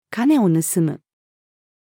金を盗む。-female.mp3